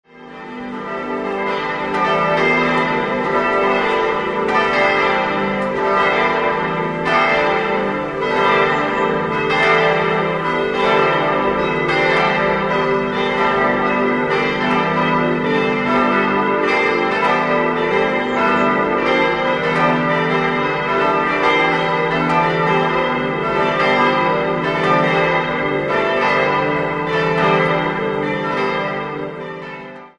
Christusglocke d' 1.804 kg 1963
Marienglocke e' 1.221 kg 1963
Thomasglocke g' 725 kg 1963
Ulrichsglocke a' 511 kg 1963
Willibaldsglocke h' 435 kg 1963